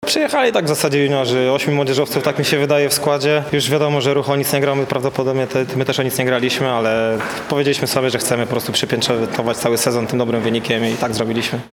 powiedział tuż po spotkaniu